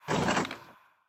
Minecraft Version Minecraft Version snapshot Latest Release | Latest Snapshot snapshot / assets / minecraft / sounds / mob / wither_skeleton / idle2.ogg Compare With Compare With Latest Release | Latest Snapshot